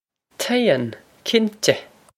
Pronunciation for how to say
Chayn, kin-teh!
This is an approximate phonetic pronunciation of the phrase.